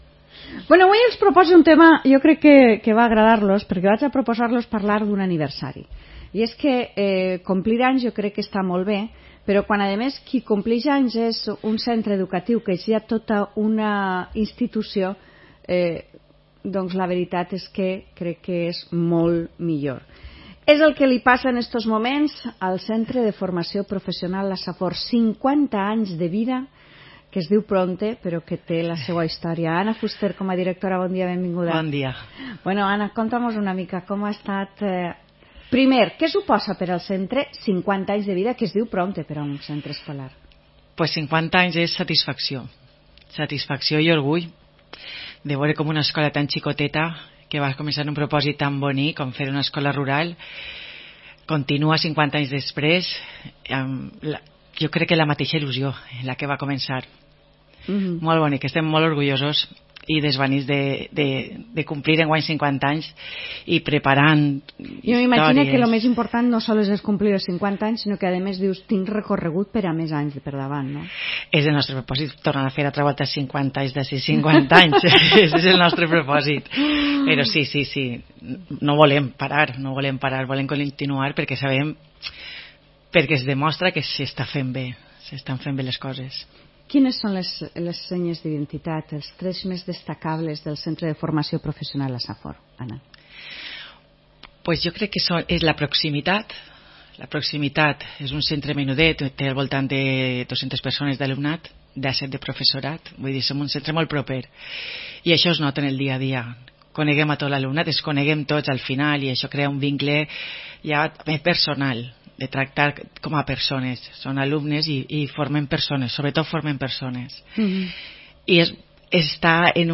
Entrevista realitzada a Onda Naranja Cope.